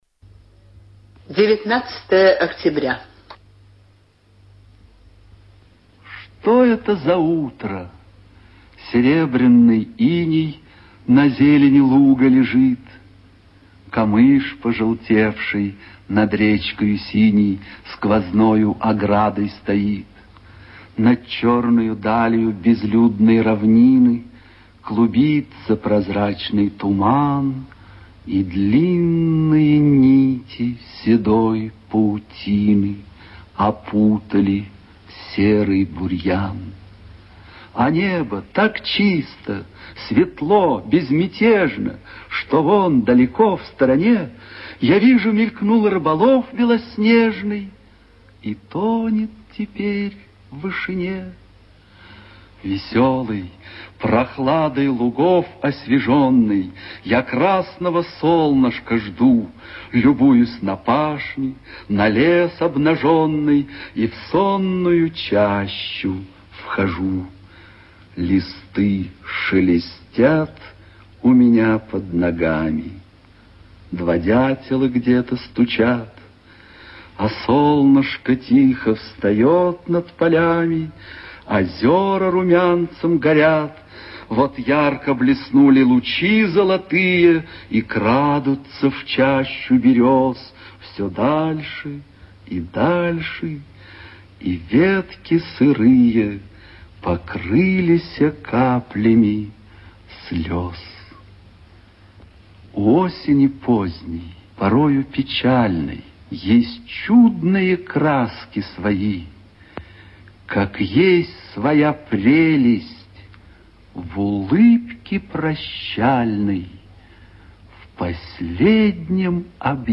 Прослушивание аудиозаписи стихотворения с сайта «Старое радио»